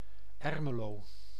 Ermelo (Sebutan Belanda: [ˈɛrməloː] (
Nl-Ermelo.ogg